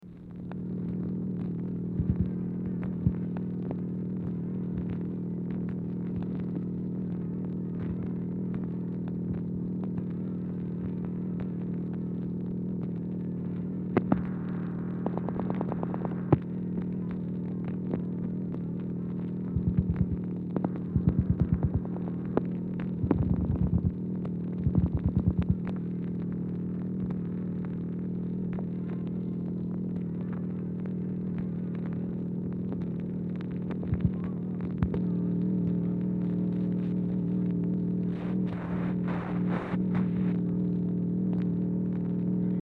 Telephone conversation # 241, sound recording, MACHINE NOISE, 12/2/1963, time unknown | Discover LBJ
Telephone conversation # 241, sound recording
VERY FAINT SOUNDS AUDIBLE IN BACKGROUND
Format Dictation belt